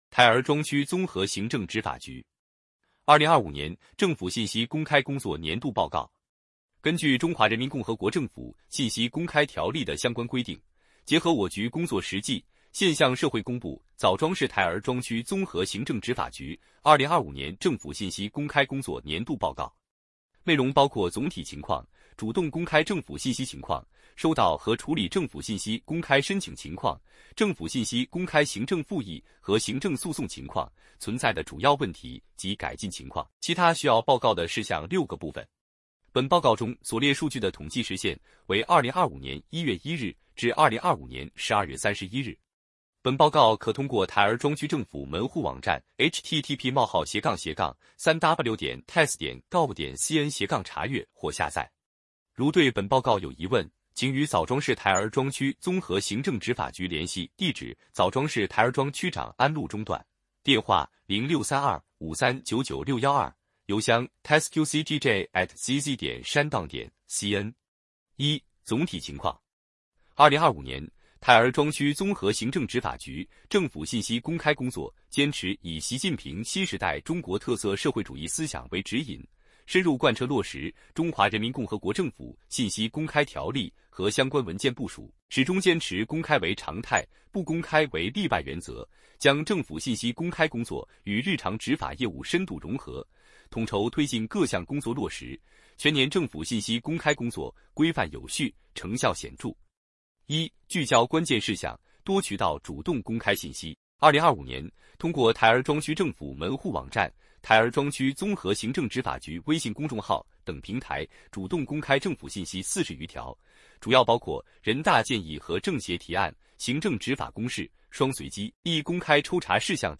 点击接收年报语音朗读 枣庄市台儿庄区综合行政执法局2025年政府信息公开工作年度报告 作者：区综合行政执法局 来自： 时间：2026-01-16 根据《中华人民共和国政府信息公开条例》的相关规定，结合我局工作实际，现向社会公布枣庄市台儿庄区综合行政执法局2025年政府信息公开工作年度报告。